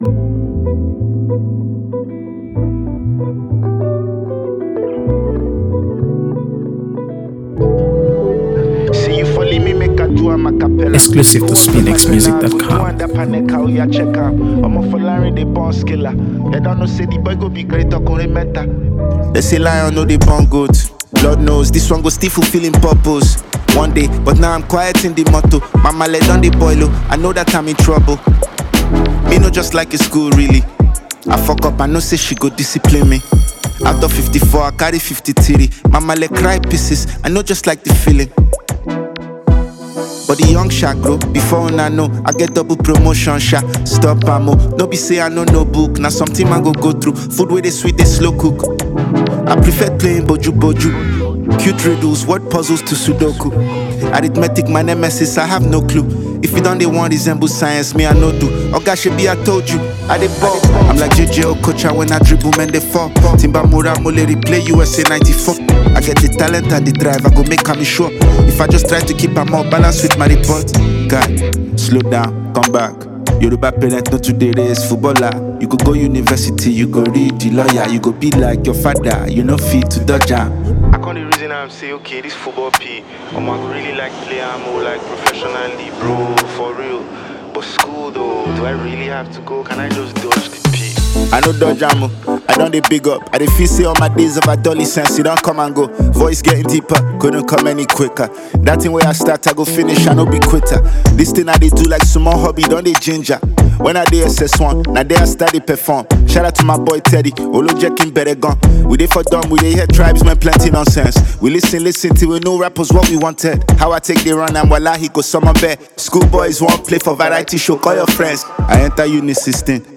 AfroBeats | AfroBeats songs
The song blends sharp lyrics with a smooth beat, drawin